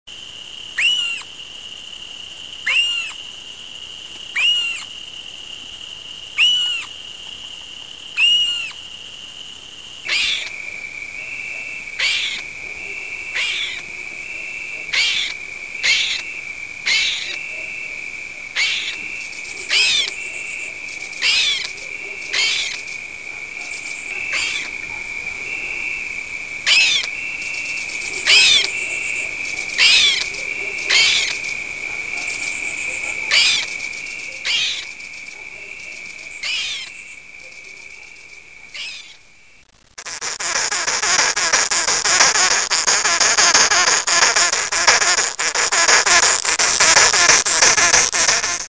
Также в брачный период полчки издают по ночам громкие крики, рычание, свист и хрюканье. В это же время полчки поют, их песни в виде «тции-тции-тции» могут продолжаться 10 минут.